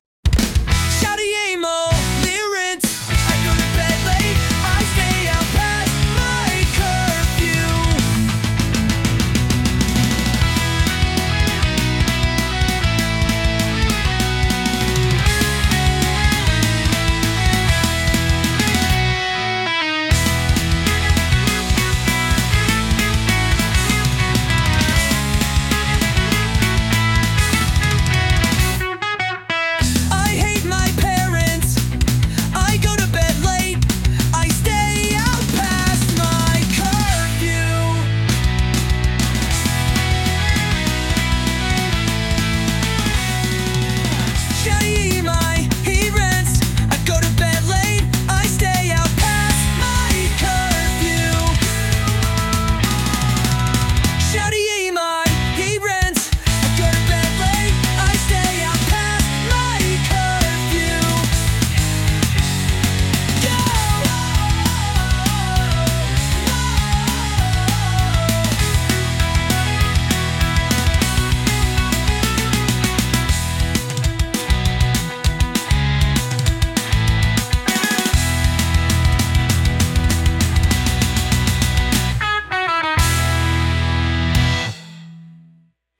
shouty emo lyrics